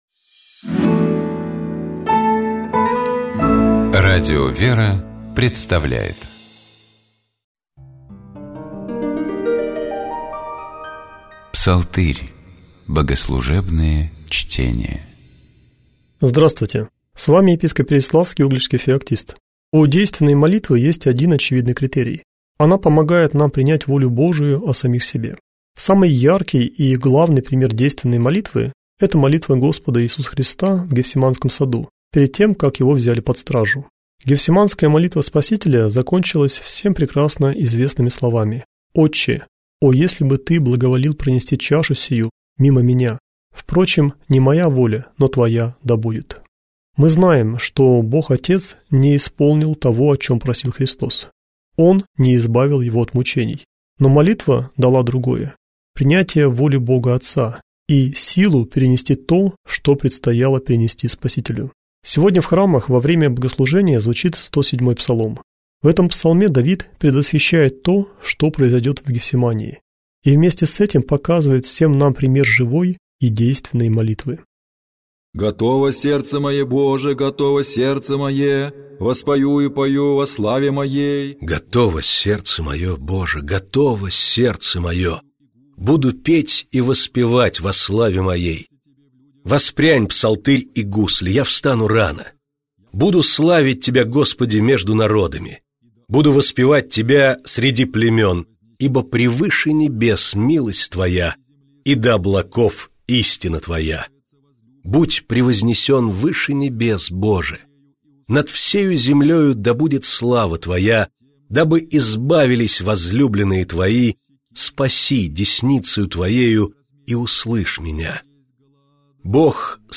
Псалом 107. Богослужебные чтения